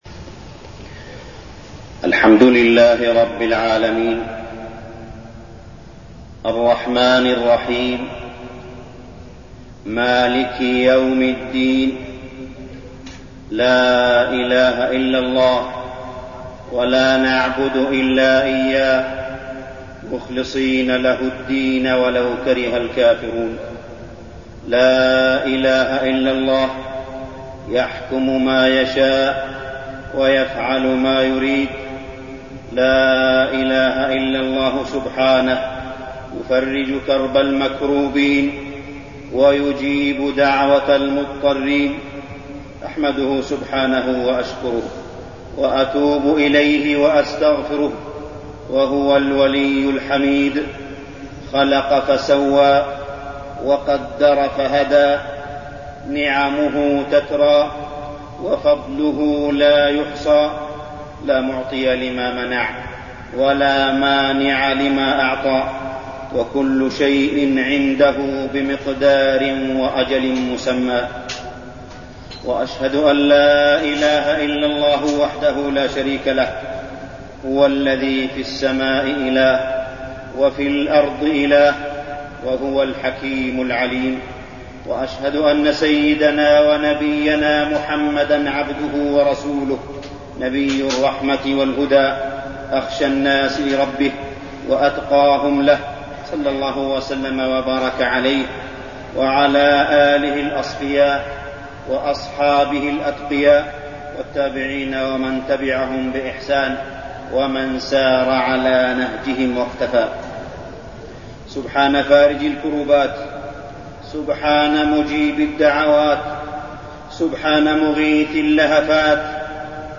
تاريخ النشر ١٩ ربيع الثاني ١٤١٠ هـ المكان: المسجد الحرام الشيخ: معالي الشيخ أ.د. صالح بن عبدالله بن حميد معالي الشيخ أ.د. صالح بن عبدالله بن حميد عواقب المعاصي